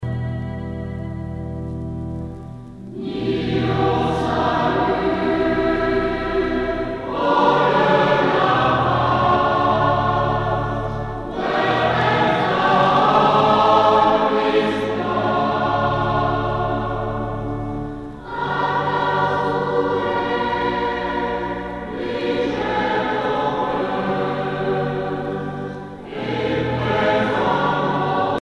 Noël vannetais
Pièce musicale éditée